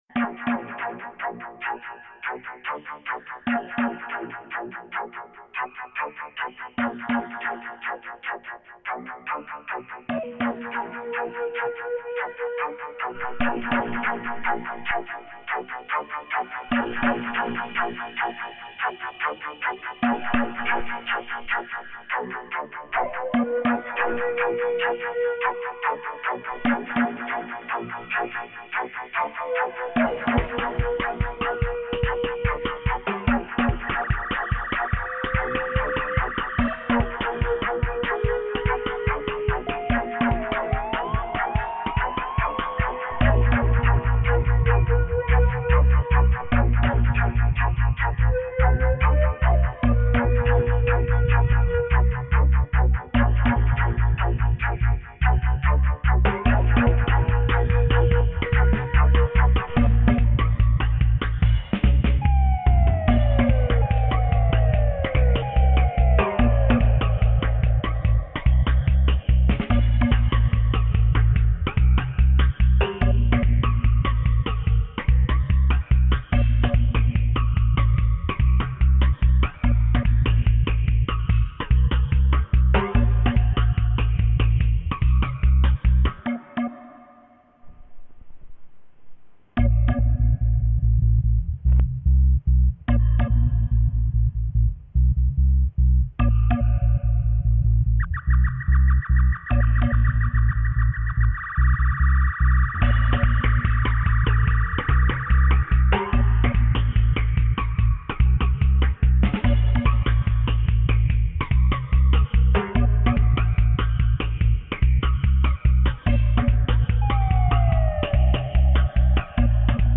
Live 2 hour Session
best reggae Roots and culture Reggae